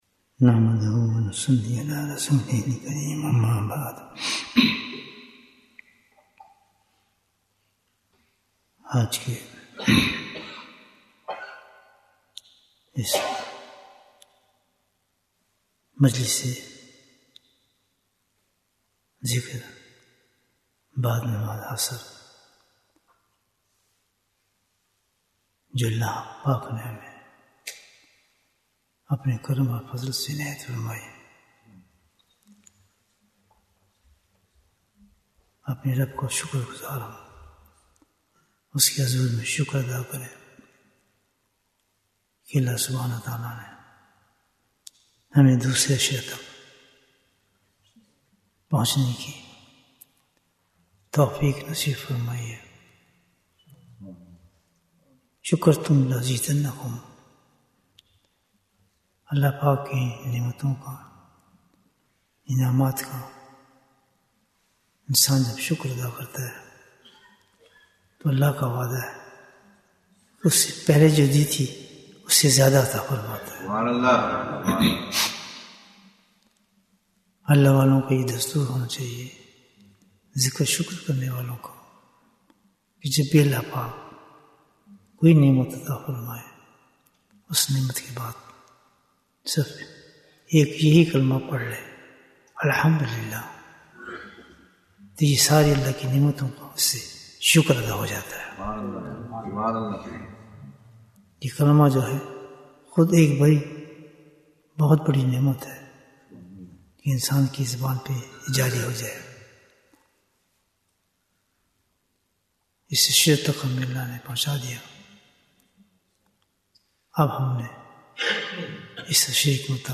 Bayan, 52 minutes 11th March, 2025 Click for English Download Audio Comments Darud e Ibrahim is not written correctly in your online zikar.